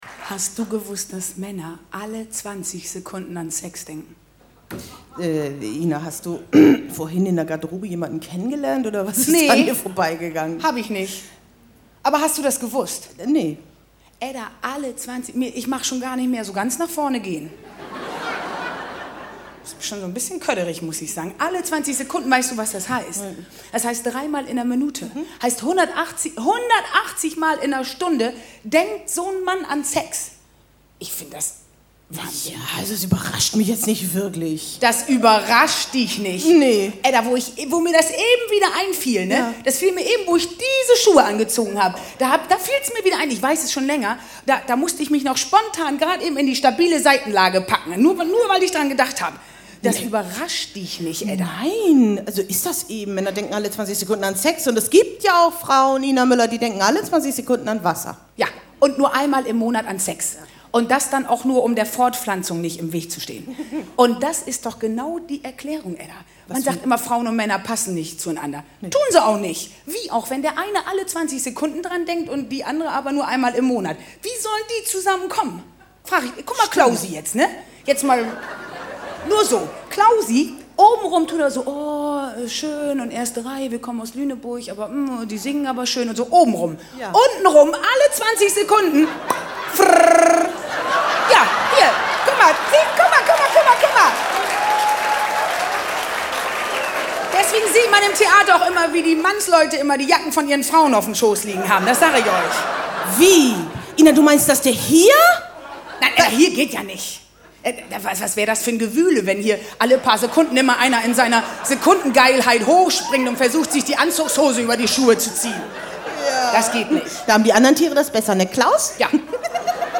Kabarett